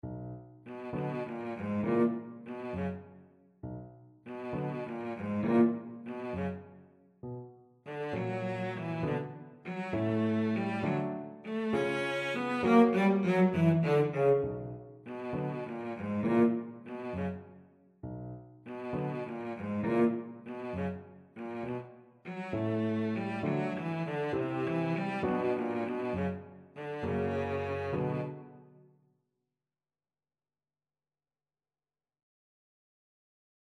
Cello version
6/8 (View more 6/8 Music)
Moderato
Cello  (View more Easy Cello Music)
Classical (View more Classical Cello Music)